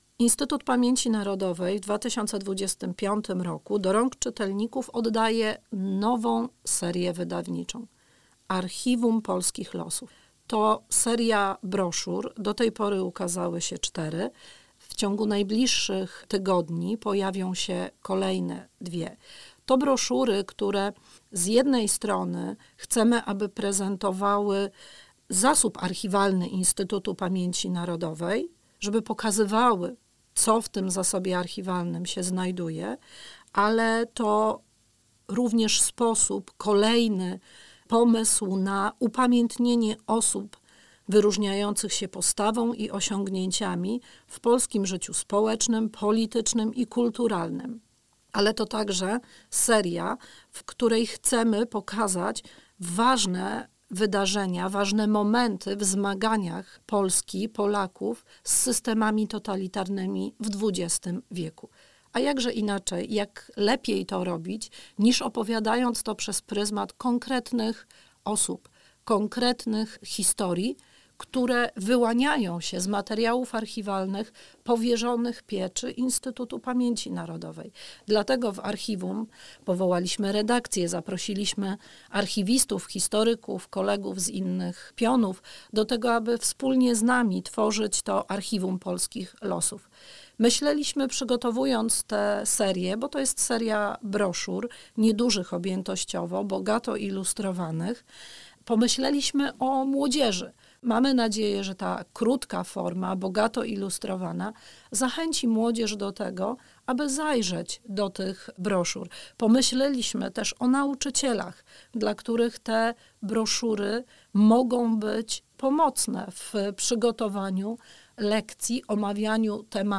19 listopada 2025 r. w Centralnym Przystanku Historia im. Prezydenta Lecha Kaczyńskiego przy ul. Marszałkowskiej 107 w Warszawie odbyła się dyskusja o przygotowywanej przez Archiwum IPN serii wydawniczej „Archiwum Polskich Losów”.